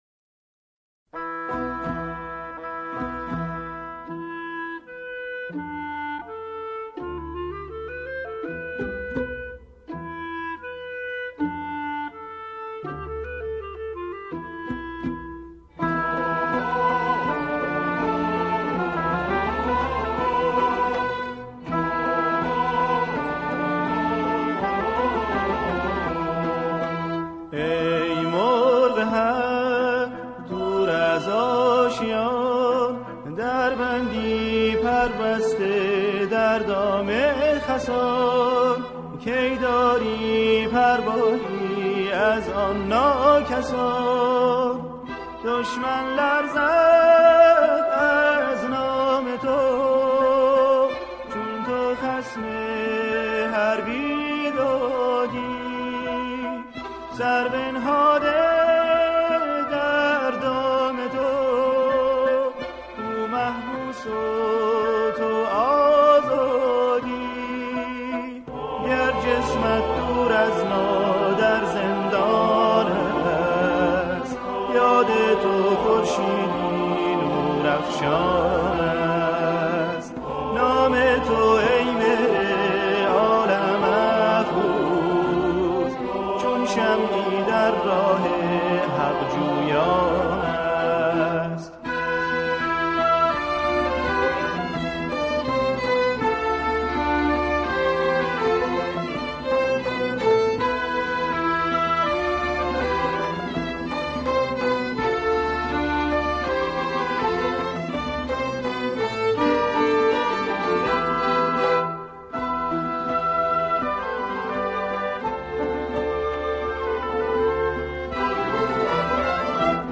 همخوانی
گروهی از همخوانان